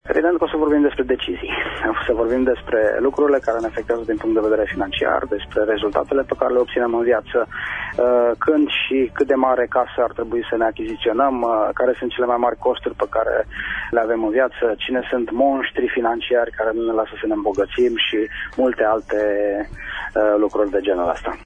extras emisiunea „Pulsul Zilei”